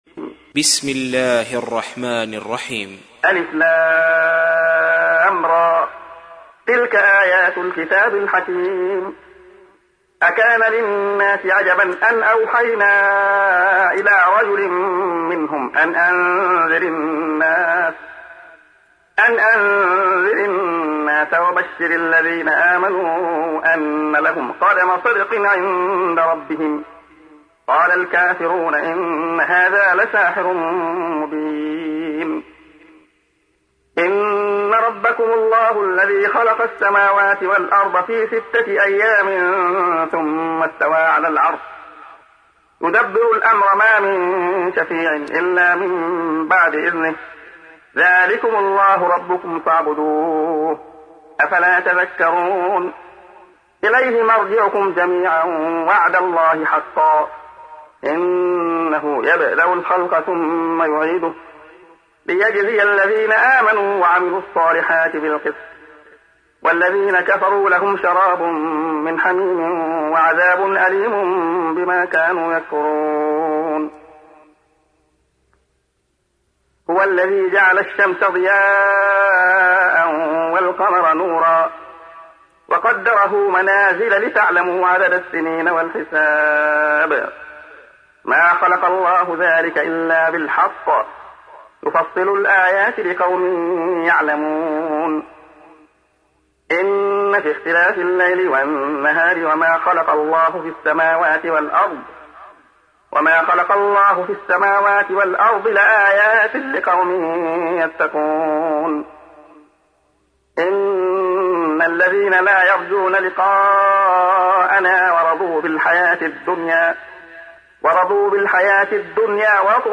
تحميل : 10. سورة يونس / القارئ عبد الله خياط / القرآن الكريم / موقع يا حسين